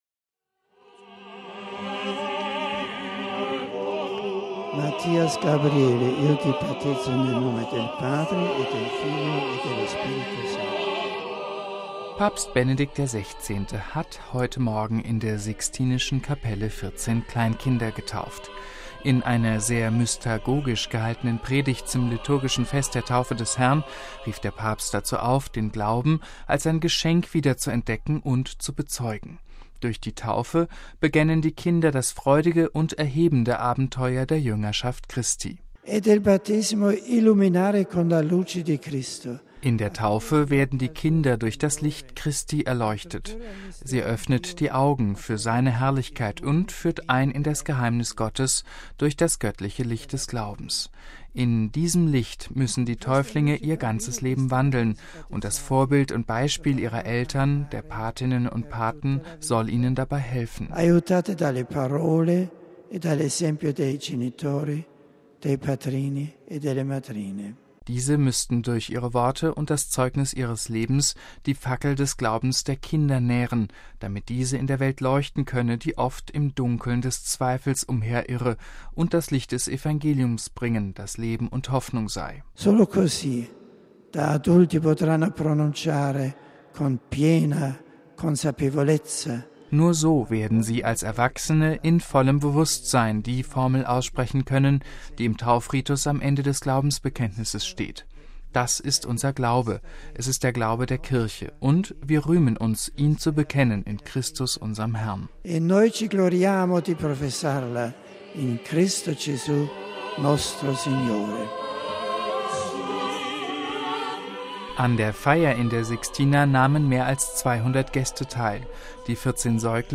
MP3 Papst Benedikt XVI. hat am Sonntag in der Sixtinischen Kapelle 14 Kleinkinder getauft.